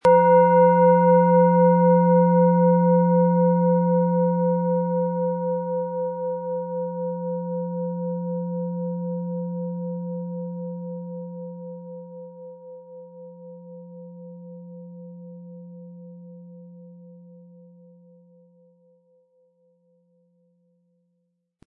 Planetenschale® Lebensenergie tanken & Leuchte und strahle mit Sonne, Ø 16,7 cm, 500-600 Gramm inkl. Klöppel
Planetenton 1
Spielen Sie die Sonne mit dem beigelegten Klöppel sanft an, sie wird es Ihnen mit wohltuenden Klängen danken.
SchalenformBihar
MaterialBronze